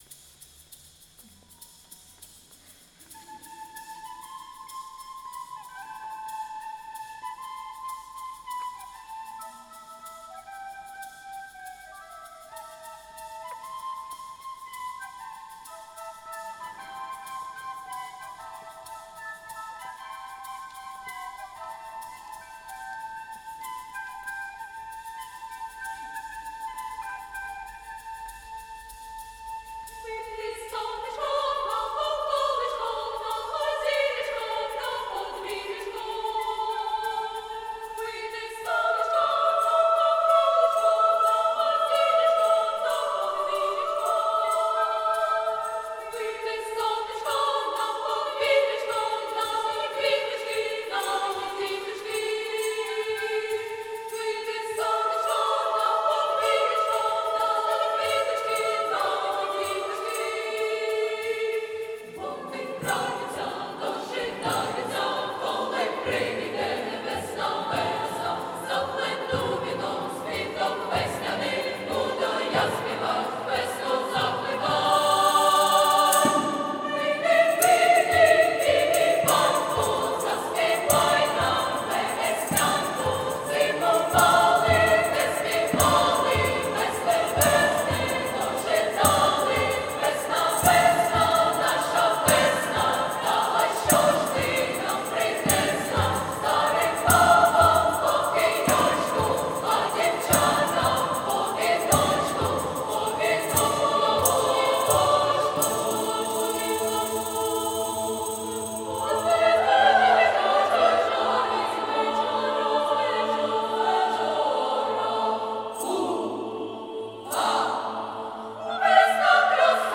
SAB div. choir, flute, percussion